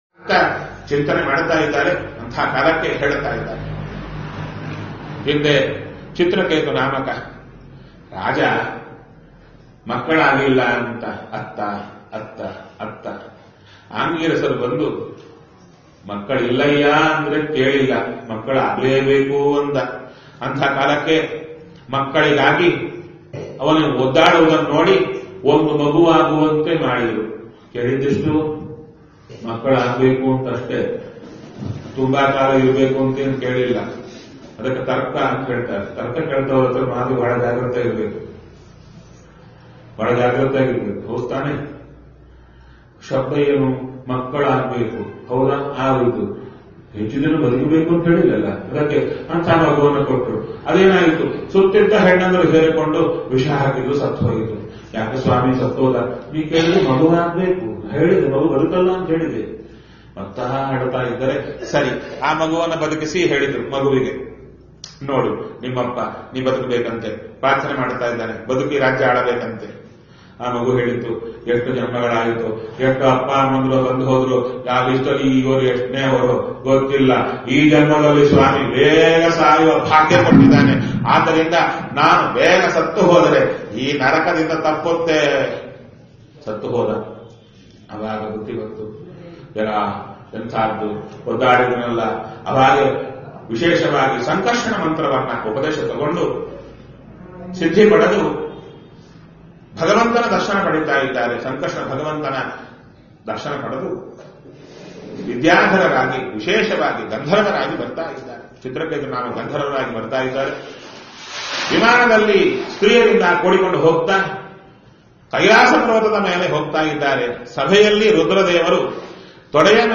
Go Home Pravachana Bhagavata Bhagavatapushkara Bhagavatha Pushkara 2.M4a Bhagavatha Pushkara 2.M4a